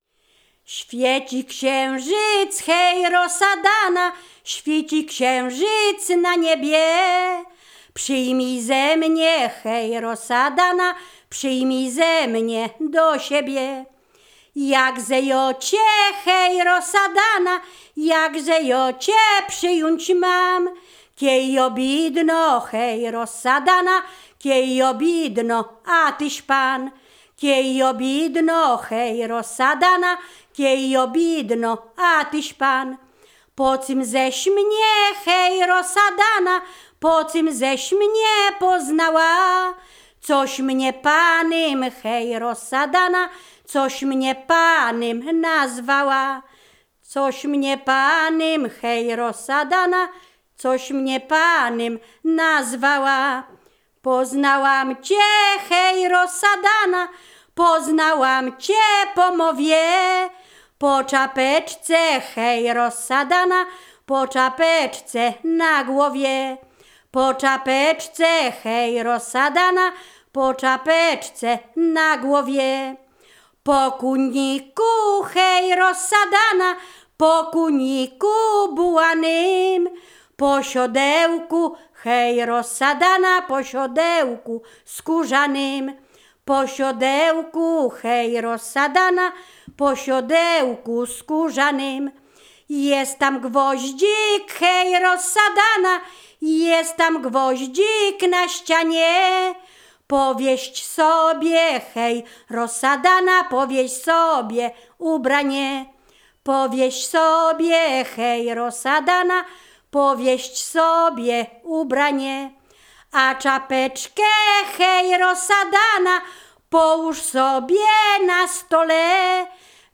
Ziemia Radomska
województwo mazowieckie, powiat przysuski, gmina Rusinów, wieś Brogowa
liryczne miłosne